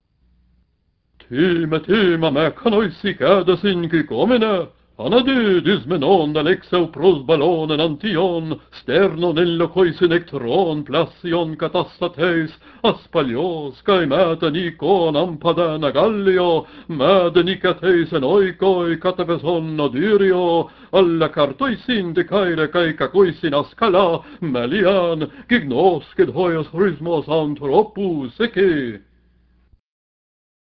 second spoken